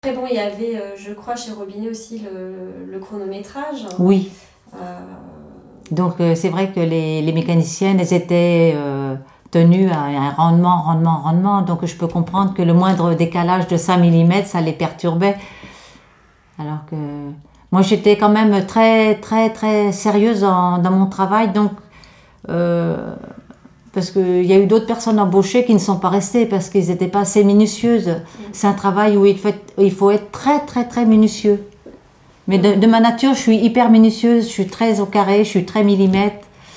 Figure 17 : Extrait vidéo du témoignage d’une ouvrière (patronnière gradeuse) en confection.